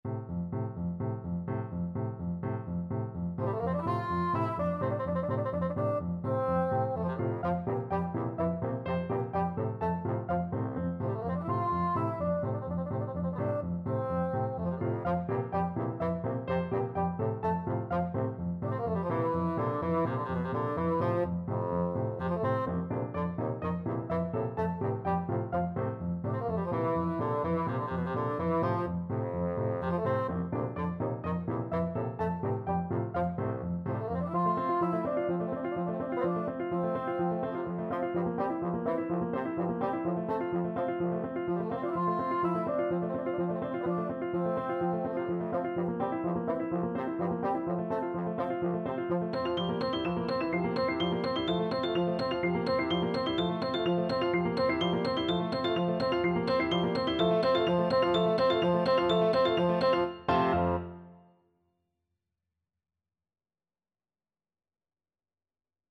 4/4 (View more 4/4 Music)
Allegro moderato (=126) (View more music marked Allegro)
C3-F5
Classical (View more Classical Bassoon Music)